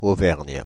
Auvergne (/ˈvɛərn(jə), ˈvɜːrn/;[2][3][4][5] French: [ovɛʁɲ]
Fr-Paris--Auvergne.ogg.mp3